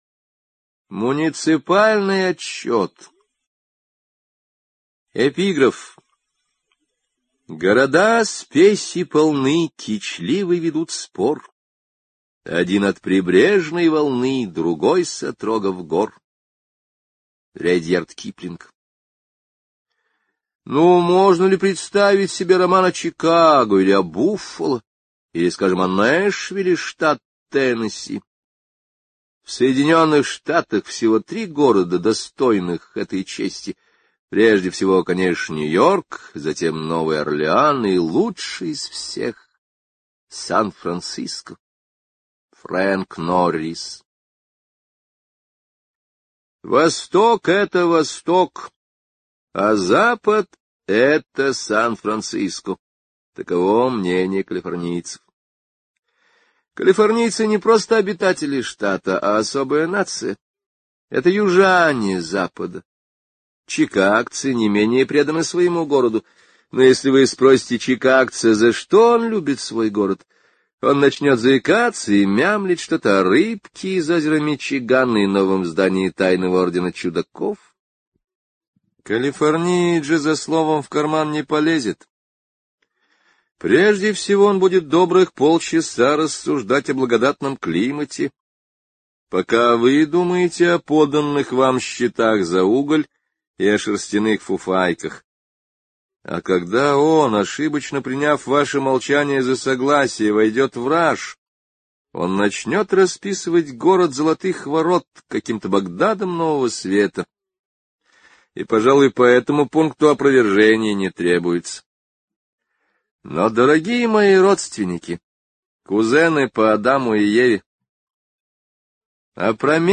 Муниципальный отчёт — слушать аудиосказку Генри О бесплатно онлайн